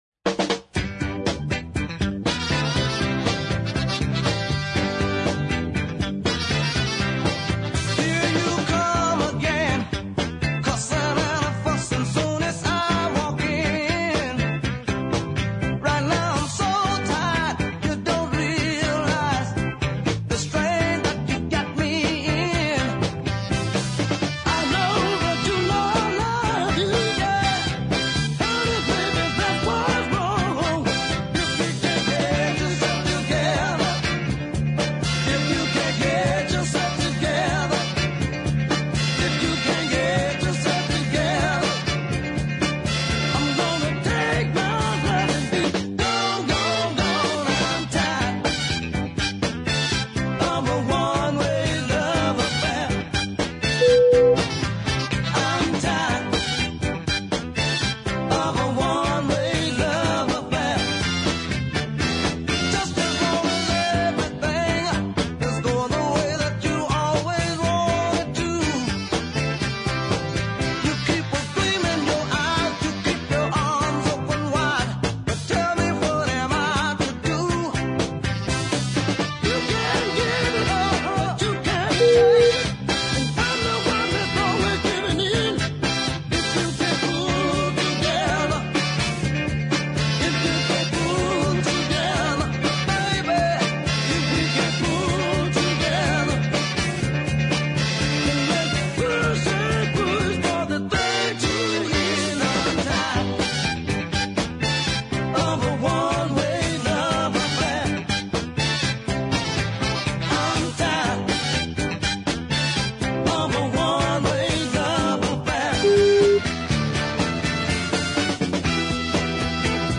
made several very good southern soul 45s in the 70s
I really like this uptempo number
great rumbling guitar / bass combo
clean horn section